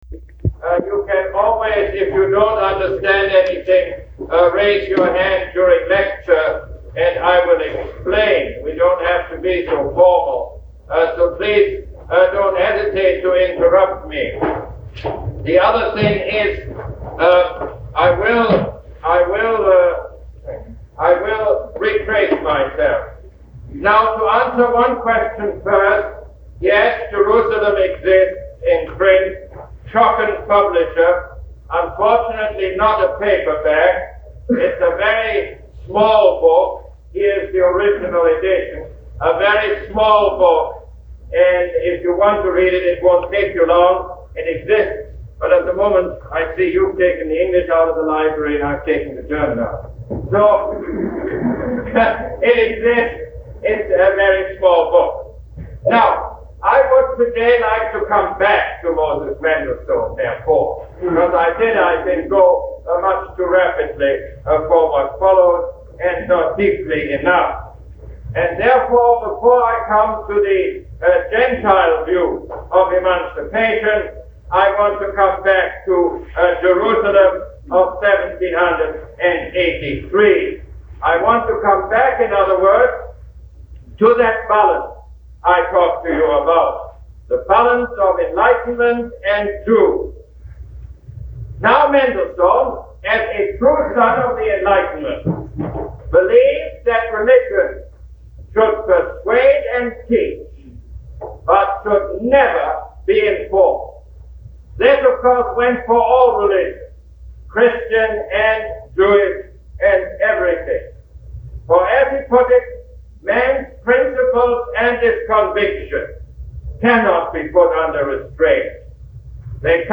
Lecture #1 - February 12, 1971